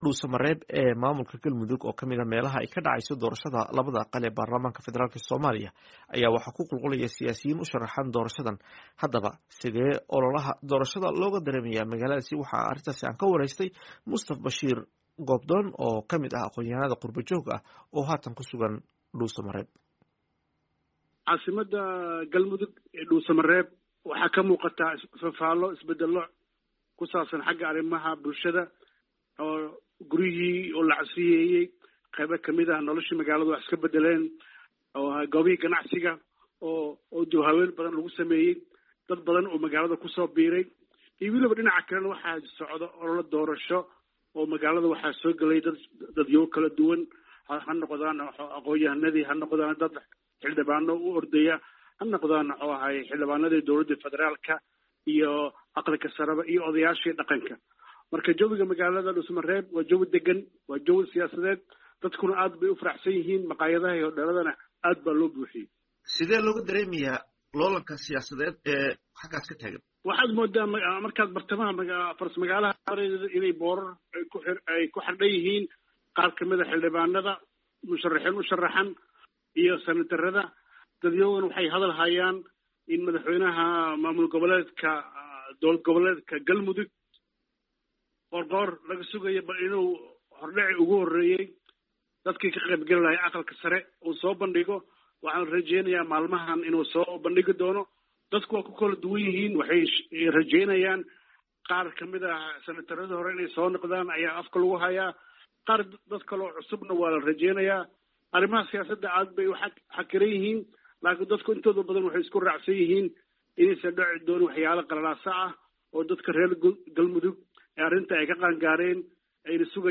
DHUUSOMAREEB —